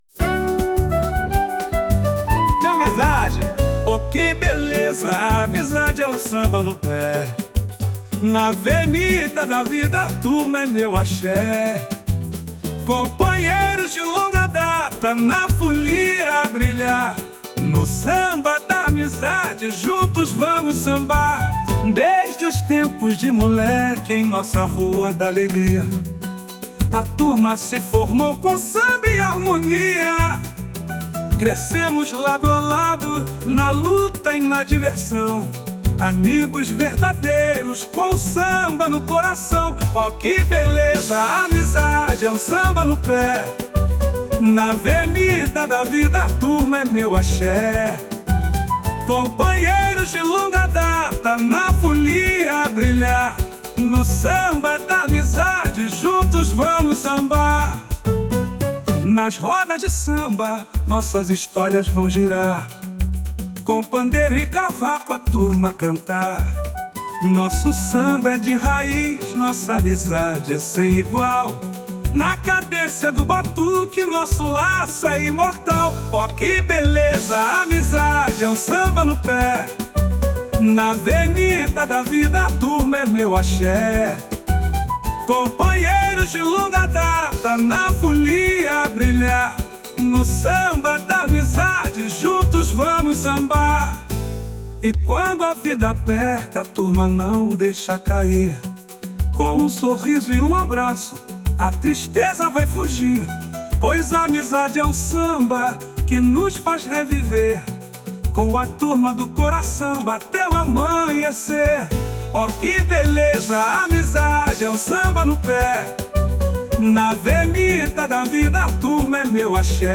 Transforme qualquer ideia em uma música incrível com voz, instrumentos de forma automática